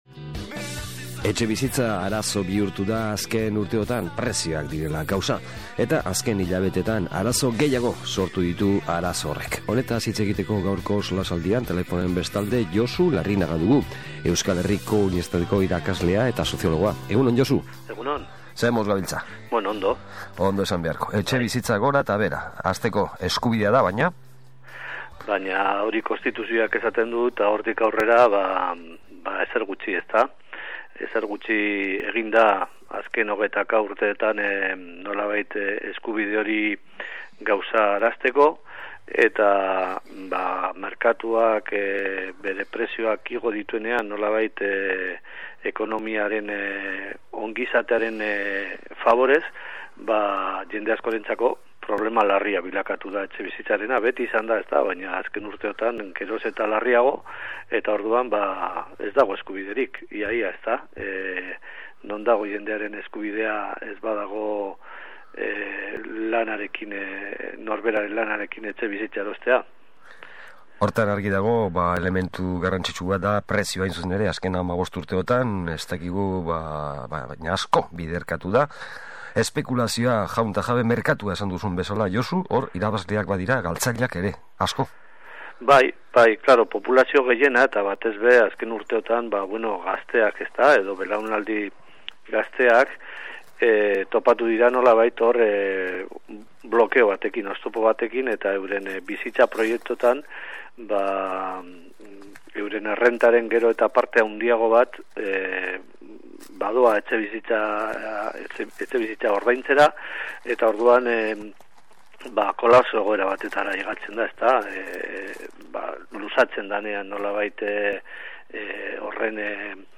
SOLASALDIA: Etxebizitzaren arazoak